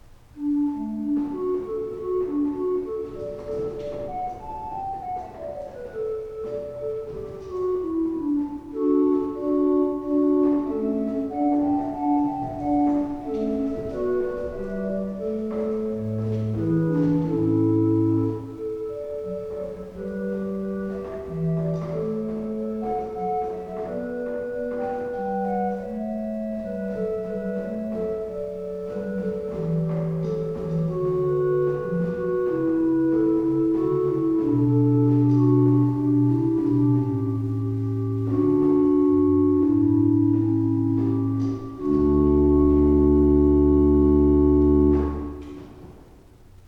Listen to an improvisation on the Gedackt 8' by clicking
Gedackt_8.wav